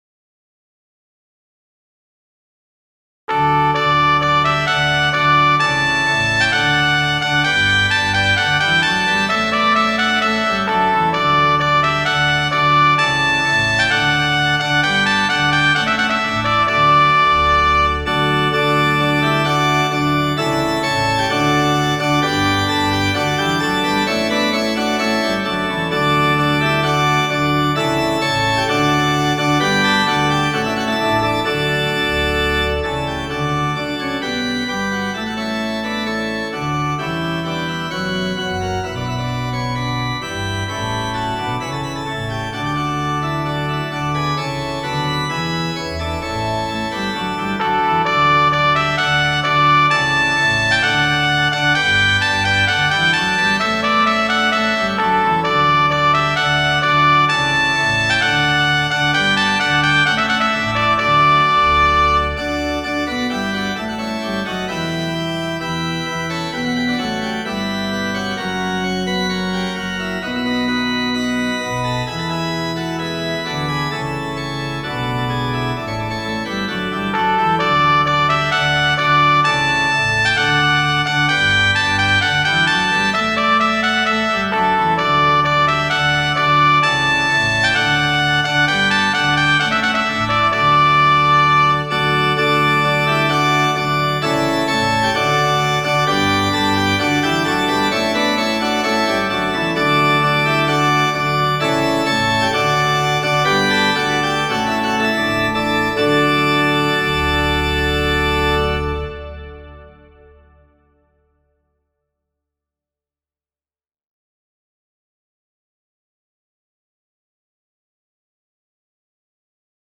Muziko: